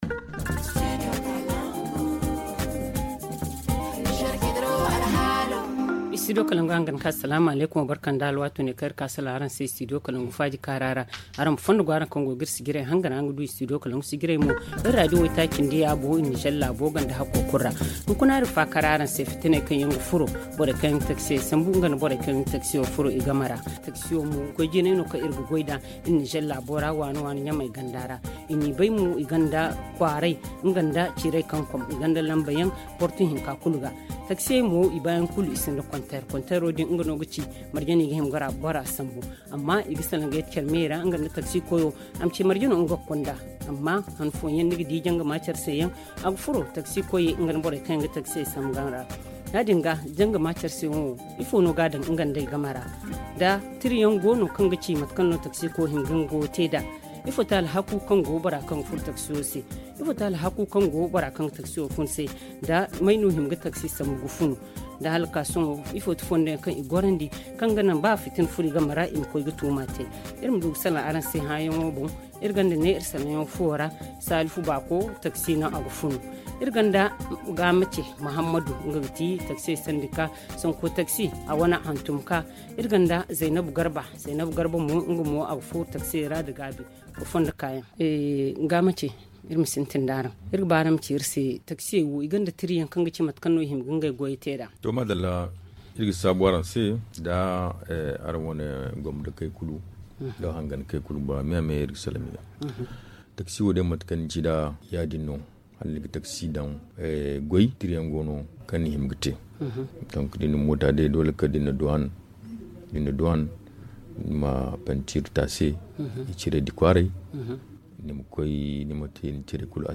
conducteur de taxi
ZA Le forum en zarma https